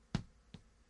Foley " 脚步声 混凝土 O D A
描述：在混凝土人行道上行走麦克风就在脚的前面SonyMD（MZN707）
标签： 福利 人力 行走 脚步
声道立体声